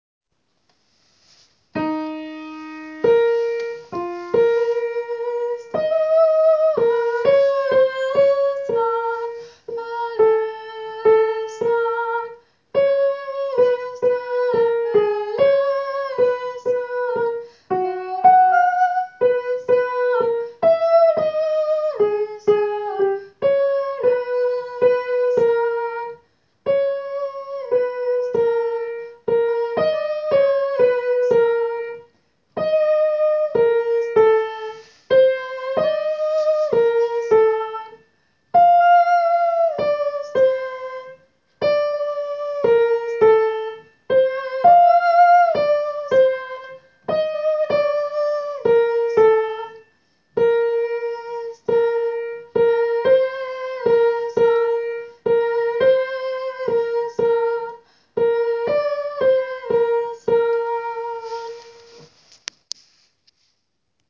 Soprano
gounod.christe.soprano.wav